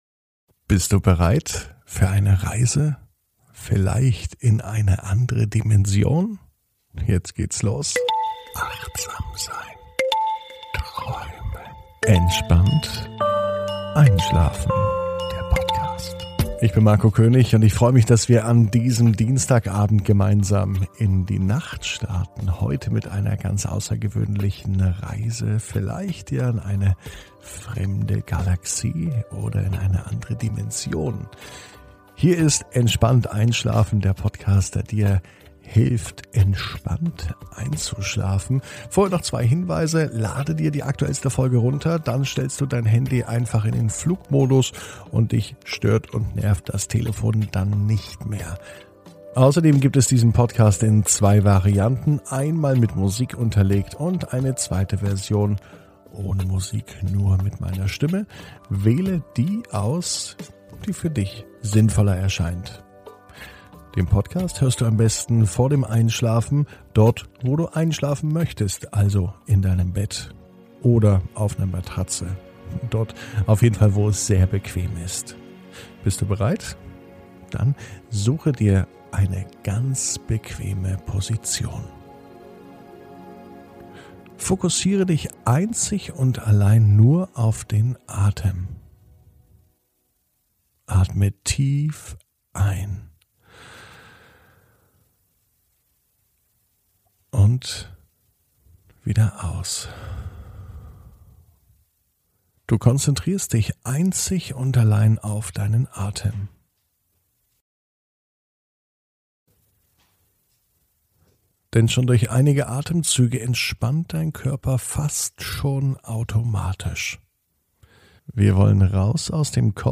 (ohne Musik) Entspannt einschlafen am Dienstag, 04.05.21 ~ Entspannt einschlafen - Meditation & Achtsamkeit für die Nacht Podcast